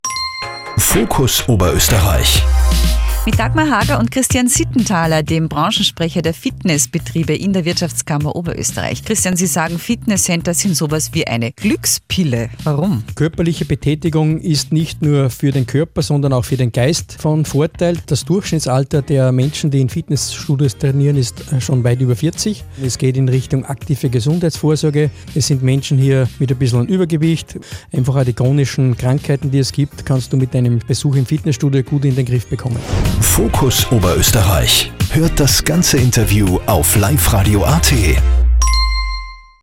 Brancheninfos zum Anhören: Life Radio Spots